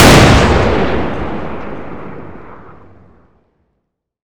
Index of /server/sound/weapons/explosive_m67
m67_explode_2.wav